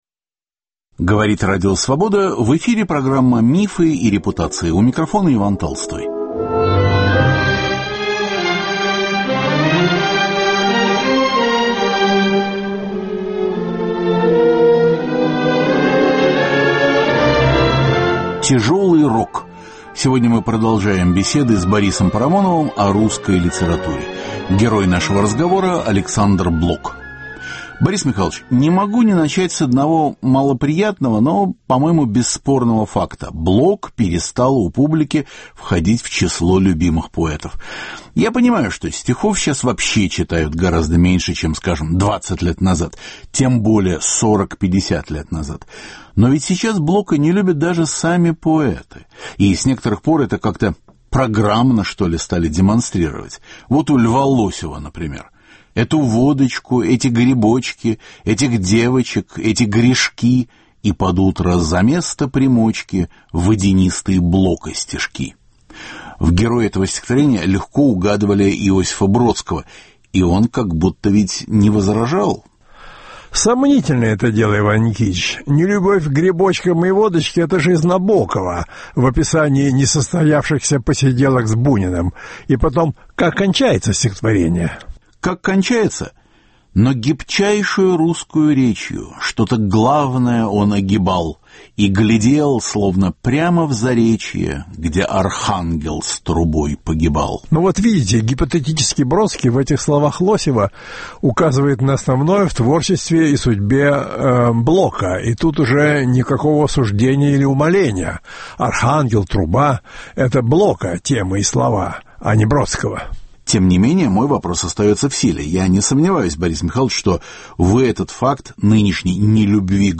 Мифы и репутации. Беседа об Александре Блоке